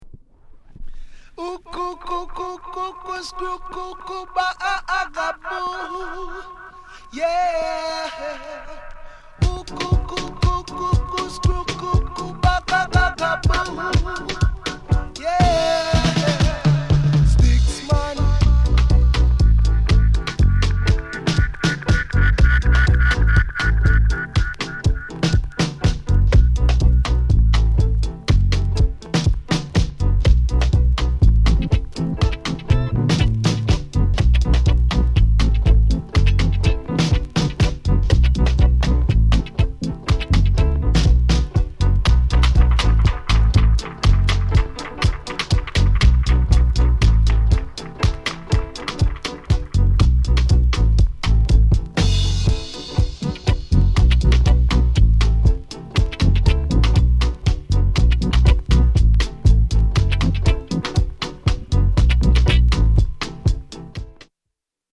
HEAVY STEPPER ROOTS